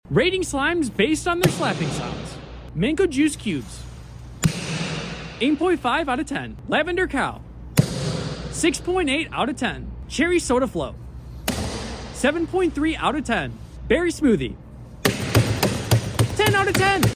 Which slime sounded the best?! sound effects free download